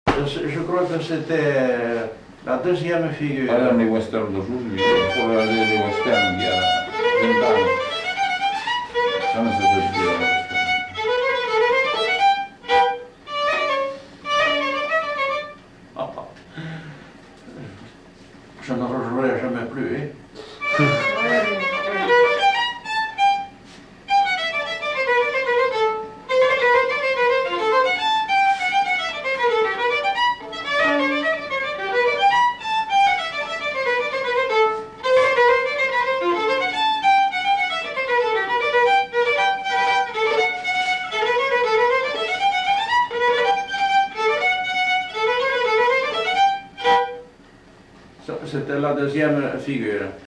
Aire culturelle : Gabardan
Lieu : Durance
Genre : morceau instrumental
Instrument de musique : violon
Danse : quadrille (2e f.)